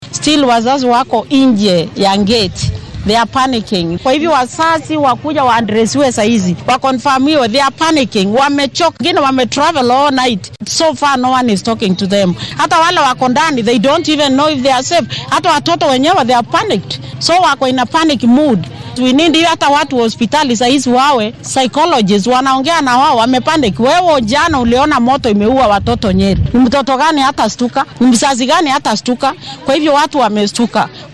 Haweeney ka mid ah shacabka Isiolo ayaa laamaha ammaanka ka dalbatay in ay waalidiinta siiyaan faahfaahin dhameystiran oo dhacdadaasi ku aaddan.